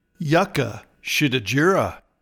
Pronounciation:
YUC-ka schi-di-GER-a